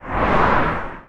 car2.ogg